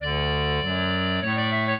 clarinet
minuet0-9.wav